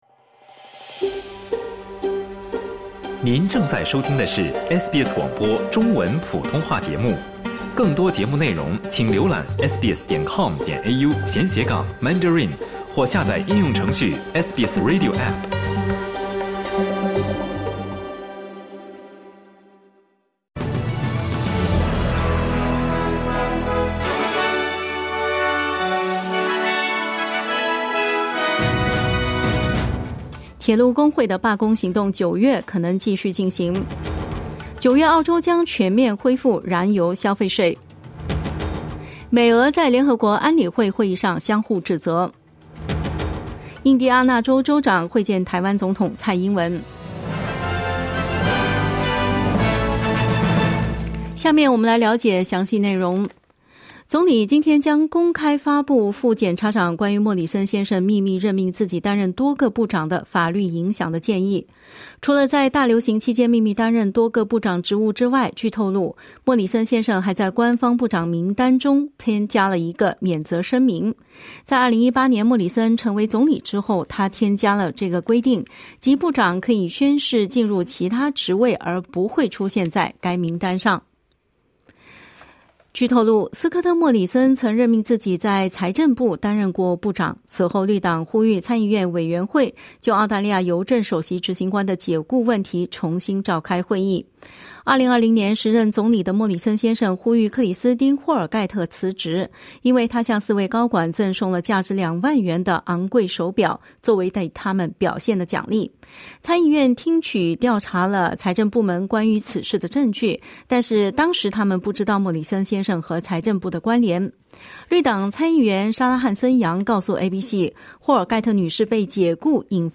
SBS早新闻（8月23日）